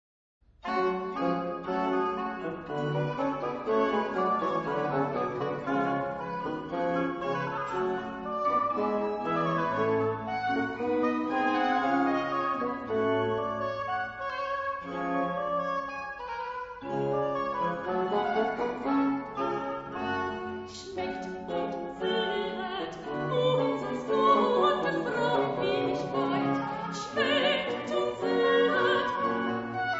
alto
soprano
• registrazione sonora di musica